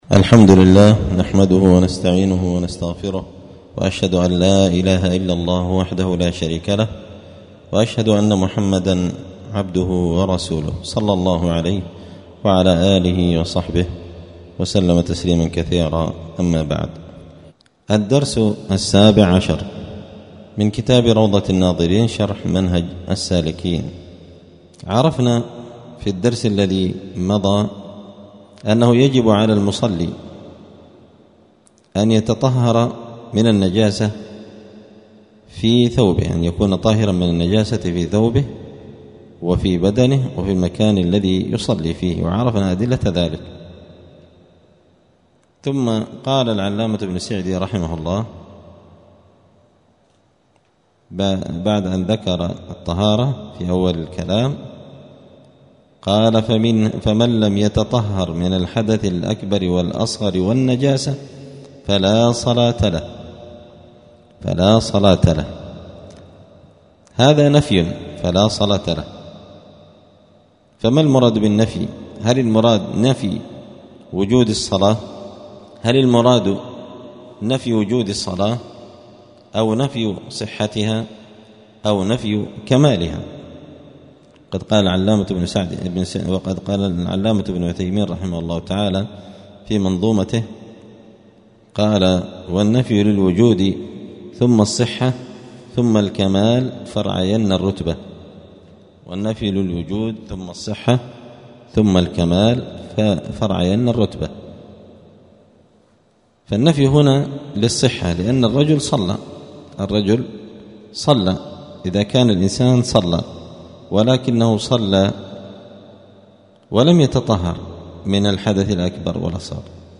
*الدرس السابع عشر (17) {كتاب الطهارة أنواع ما يتطهر به الماء والتراب}*